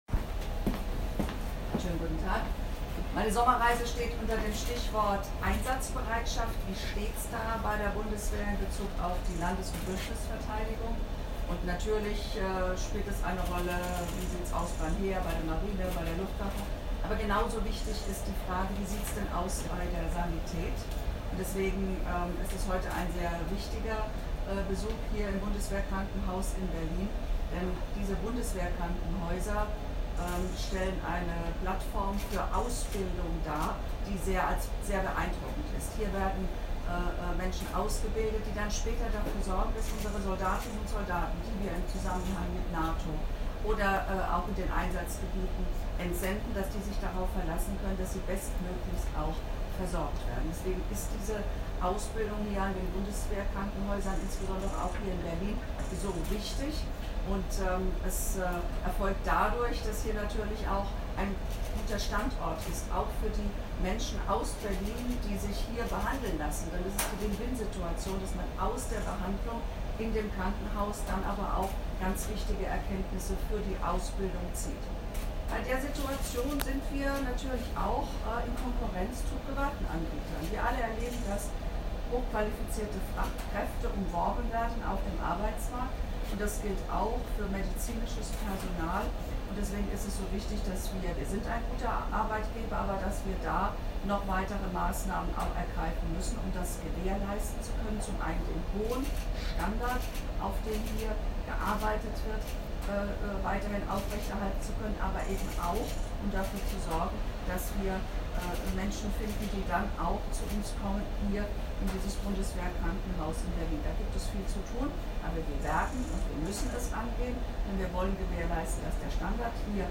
statement-der-verteidigungsministerin-data.mp3